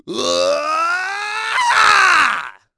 Ezekiel-Vox_Casting3.wav